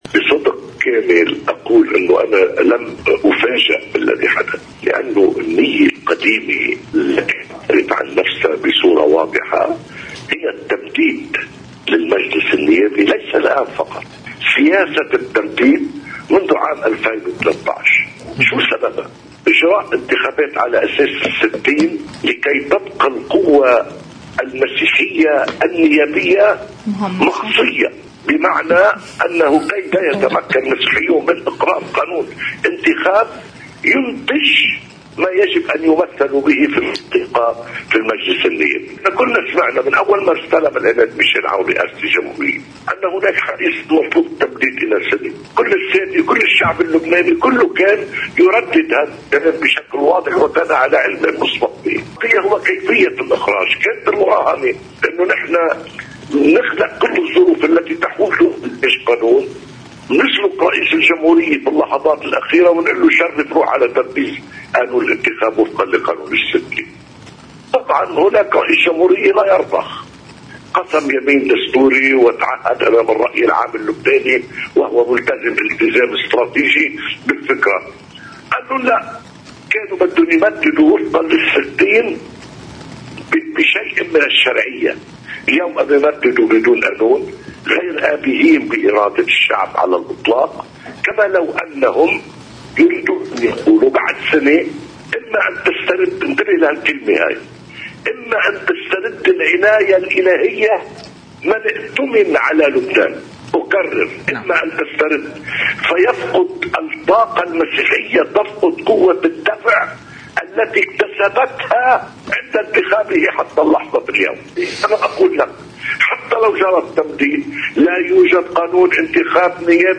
مقتطف من حديث نائب رئيس مجلس النواب السابق ايلي الفرزلي لقناة الـ”OTV”: